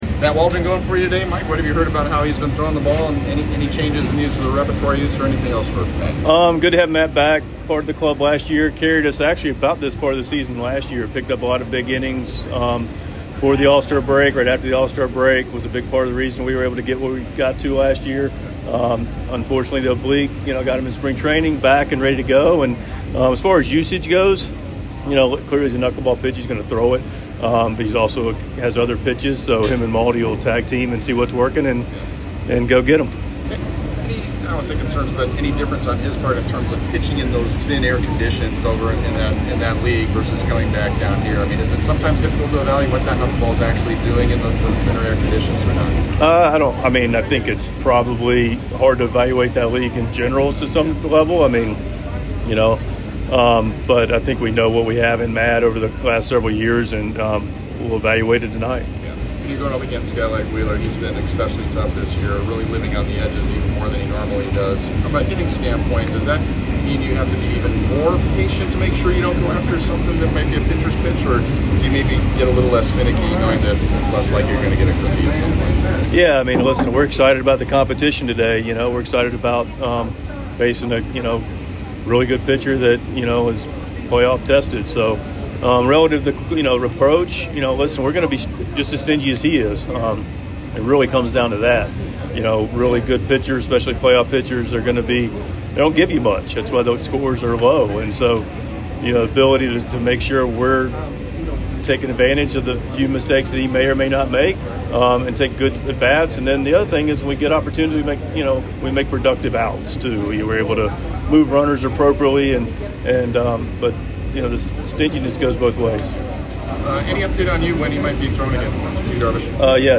Padres manager Mike Shildt speaks with the media before the team's series opener against the Phillies.